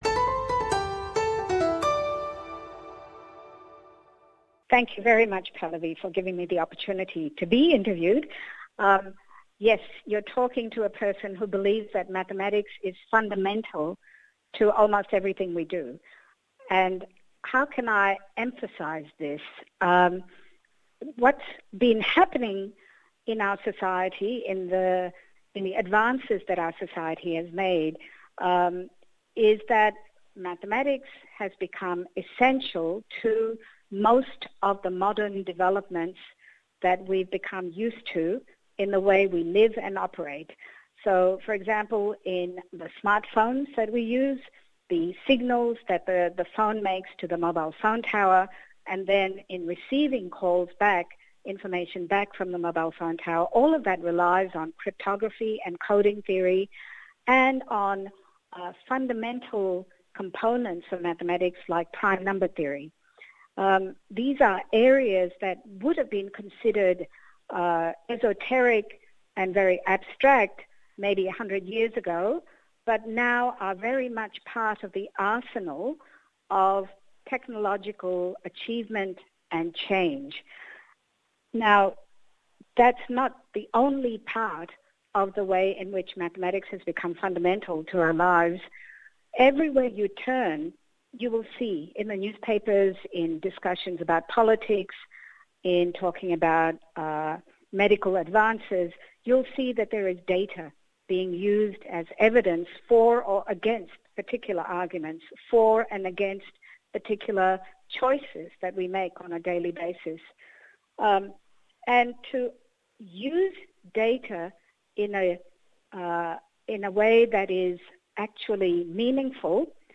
Tune in to this very special interview with the one and only Professor Nalini Joshi.